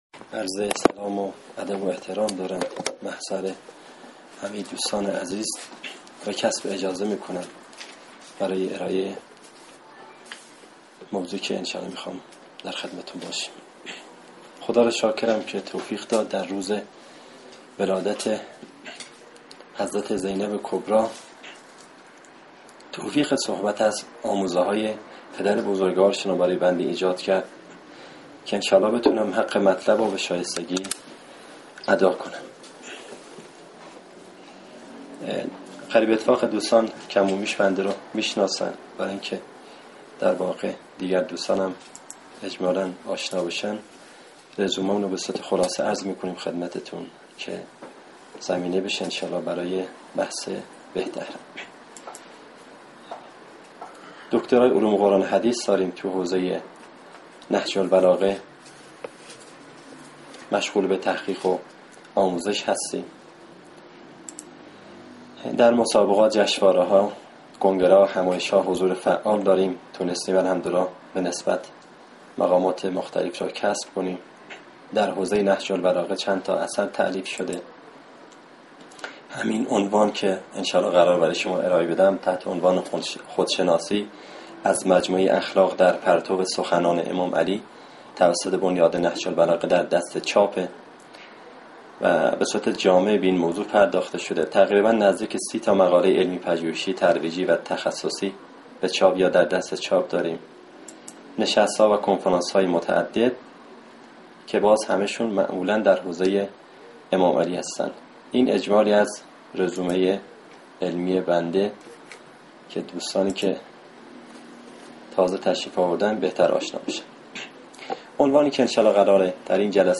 نشست علمی مرکز تحقیقات امام علی علیه السلام